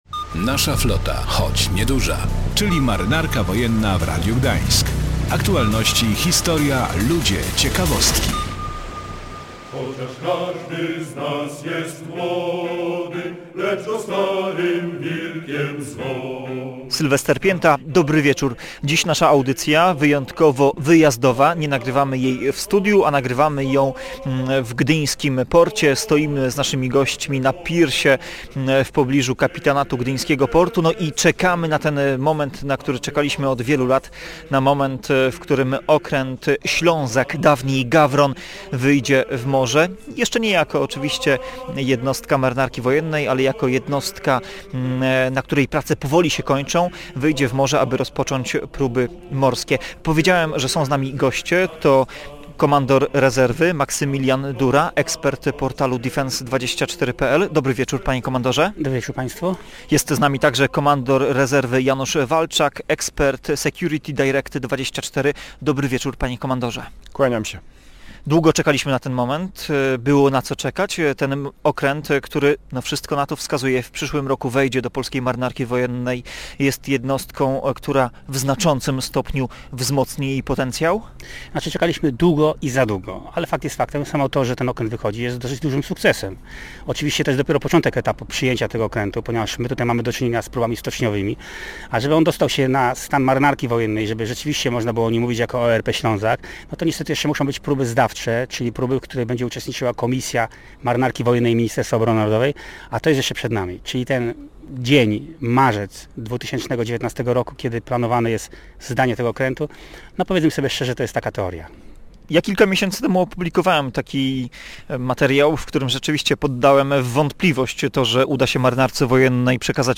Dziś audycja wprost z pirsu w pobliżu Kapitanatu Portu Gdynia.
Między innymi o tym rozmawiamy z naszymi gośćmi.